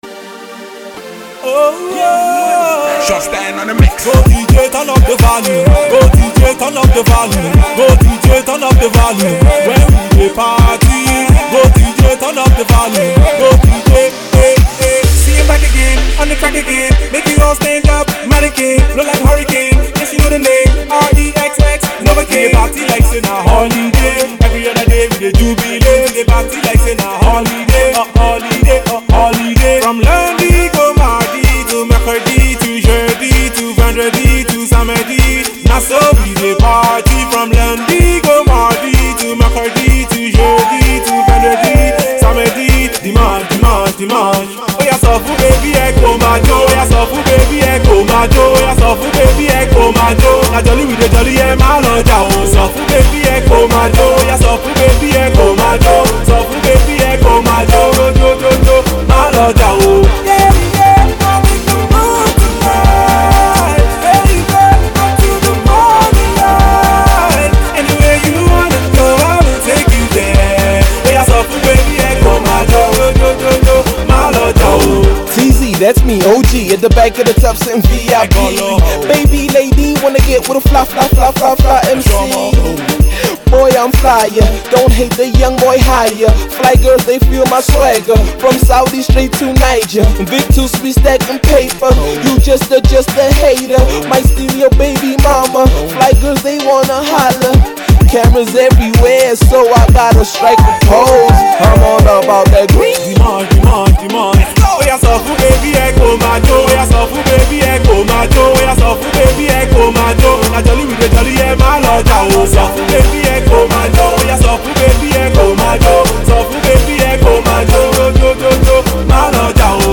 funky “jollification” tunes to make you dance and groove.
rapper
destined to rock the clubs.